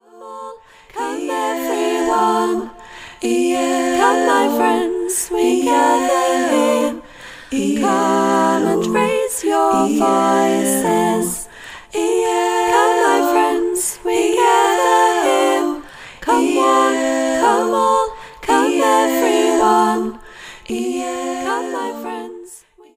A song for group singing
Parts – 4